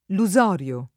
vai all'elenco alfabetico delle voci ingrandisci il carattere 100% rimpicciolisci il carattere stampa invia tramite posta elettronica codividi su Facebook lusorio [ lu @0 r L o ] agg.; pl. m. ‑ri (alla lat. -rii )